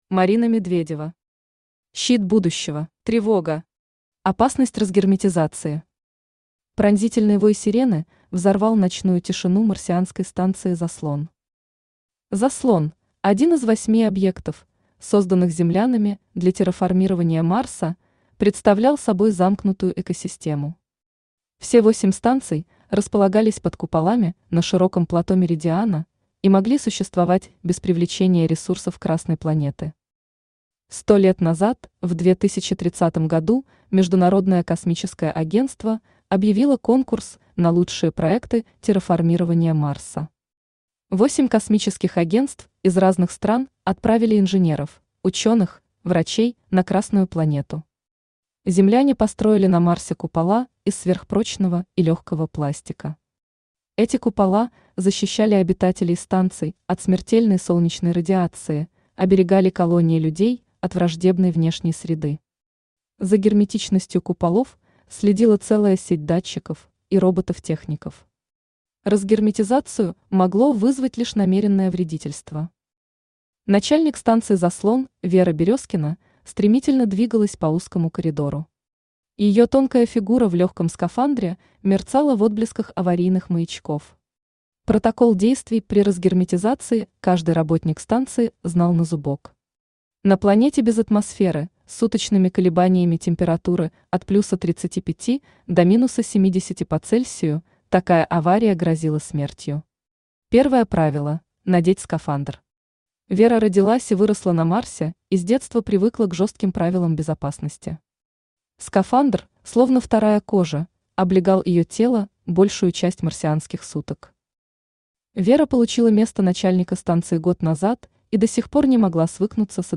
Аудиокнига Щит будущего | Библиотека аудиокниг
Aудиокнига Щит будущего Автор Марина Васильевна Медведева Читает аудиокнигу Авточтец ЛитРес.